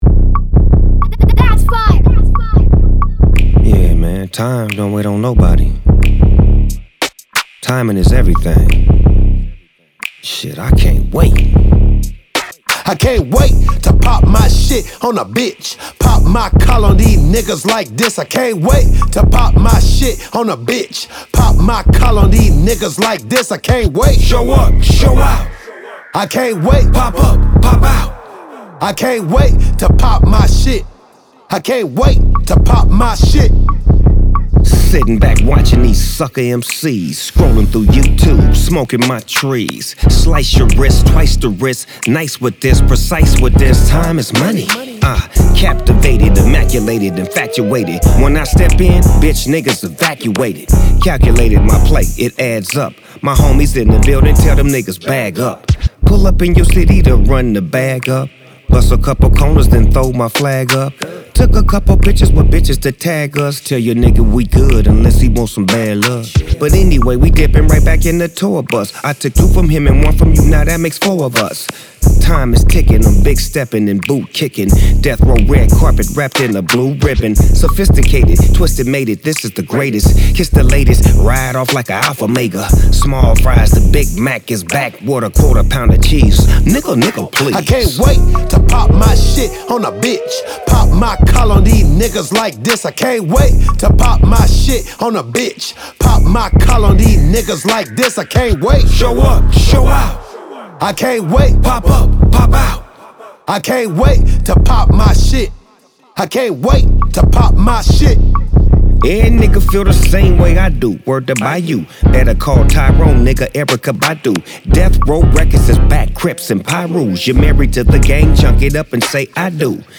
Your Source For Hip Hop News
Infused with the unmistakable spirit of West Coast hip-hop